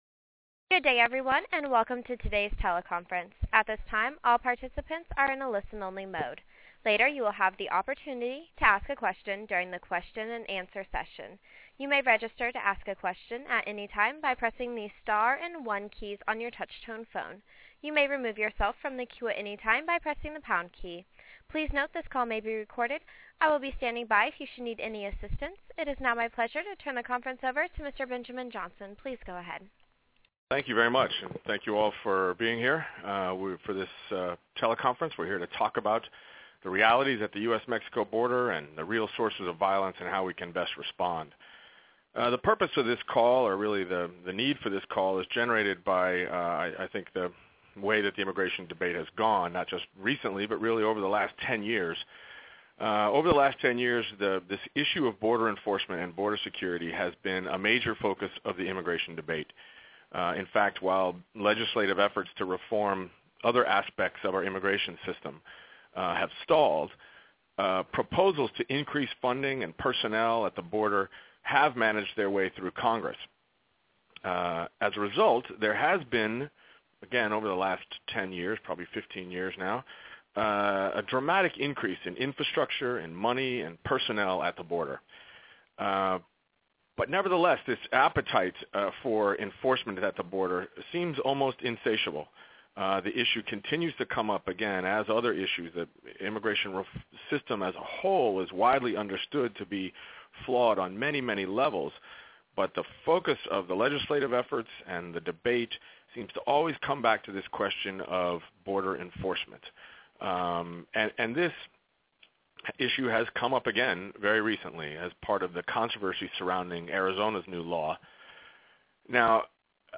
Washington D.C. - On Monday, the Immigration Policy Center (IPC) hosted a teleconference with border and national-security experts who dissected the myths linking immigration and border violence. These experts shared their analyses of the reality of crime and violence along the U.S.-Mexico border, what the real sources of violence are, and how the U.S. should respond.